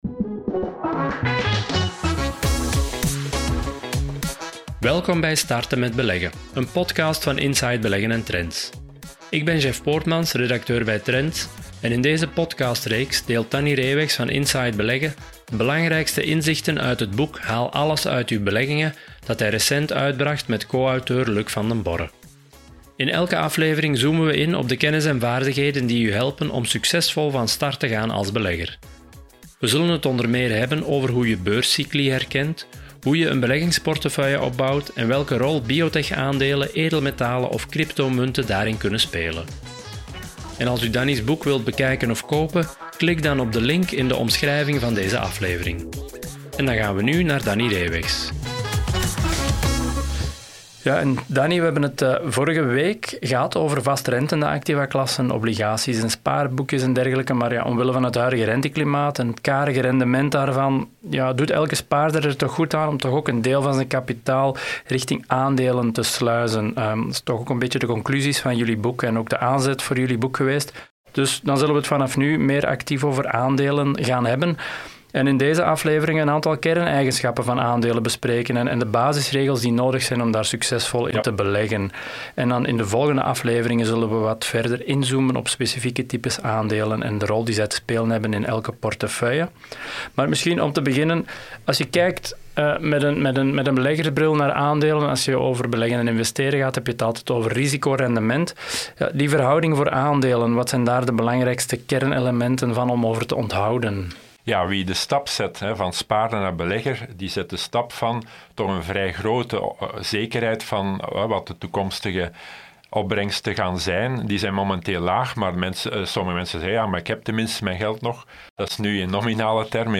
gaat elke woensdag in gesprek met de journalisten van Trends en geeft u achtergrond en analyse voor uw beleggingen. Met wekelijks een inkijk in de recente evoluties op de financieel economische actualiteit en telkens één opmerkelijk aandeel, bedrijf of sector onder de loep genomen.